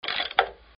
修复成功.mp3